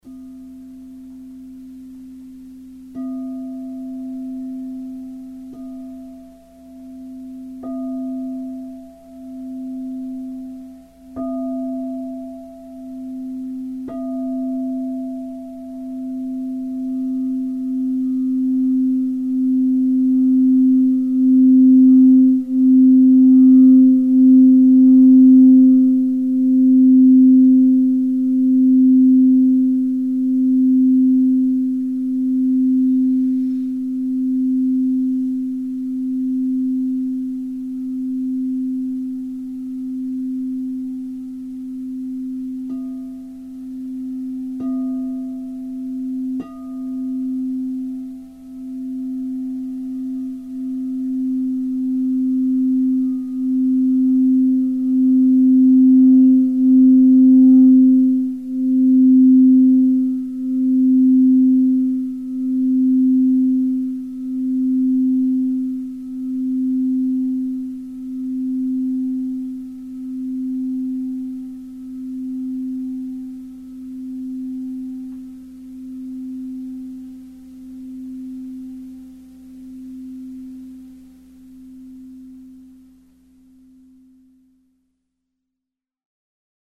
Singing Bowls
The internal spiral structure and the external rounded form of Crystal Singing Bowls produce a non linear, multidirectional Sound.
This process makes the bowls strong and incredibly pure in tone.
Son_Chakra_C_No.mp3